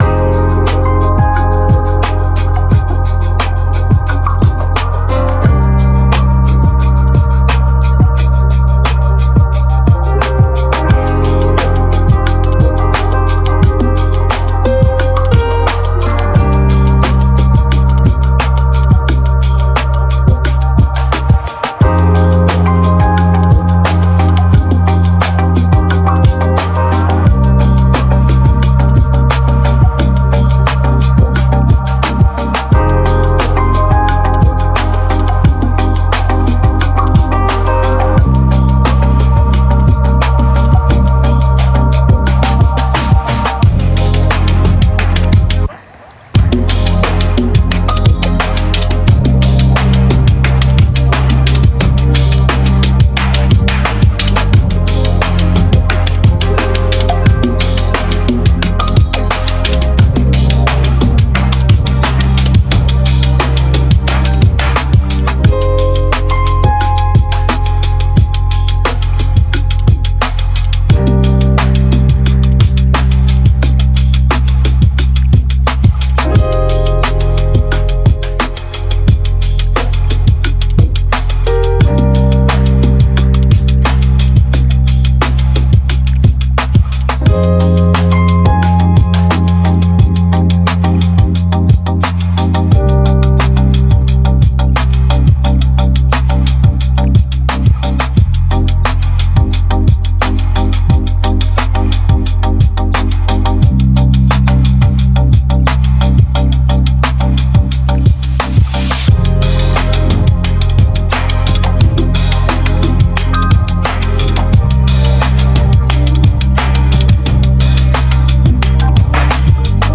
Genre Chillout